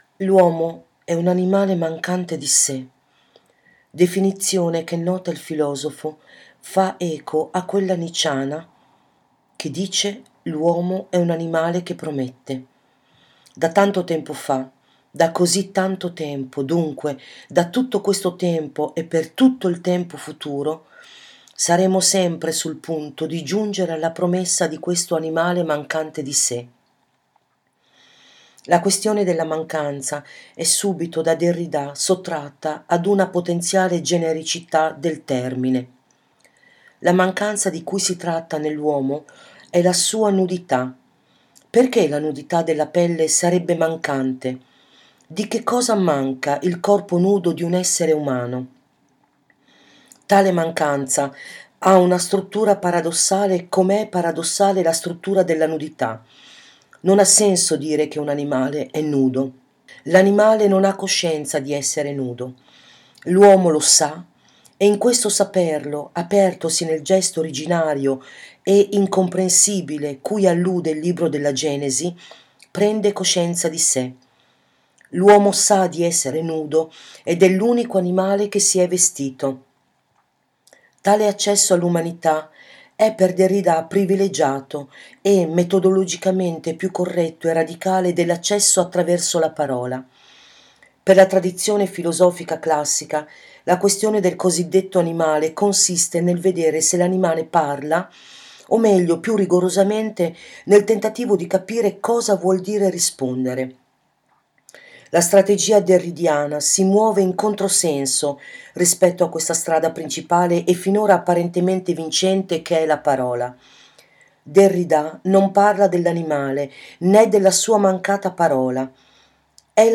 audio-performance-da-solo.mp3